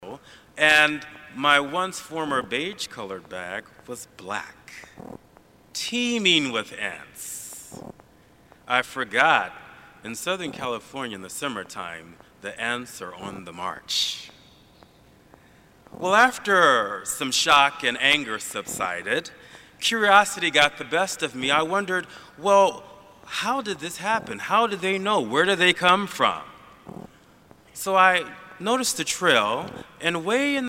That noise is coming from the tape or the cassette deck, as it repeats in perfect time.
Well this was recorded of my mp3 player thru the fm radio so I dont have cassettes just the mp3 files that were created each recording was recorded at 192kbps.
Talk 2 seconds, noise 1 second, Talk 2 seconds, noise 1 second.
It takes up the same spectrum as the human voice, which means if you try to eq out the buzz, the lecture will be affected as well.
buzz_problem_thruout.mp3